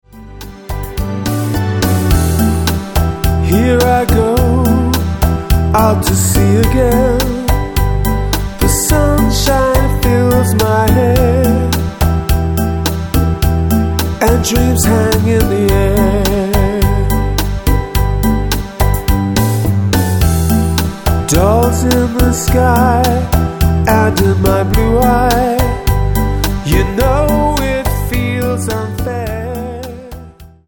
--> MP3 Demo abspielen...
Tonart:Em Multifile (kein Sofortdownload.
Die besten Playbacks Instrumentals und Karaoke Versionen .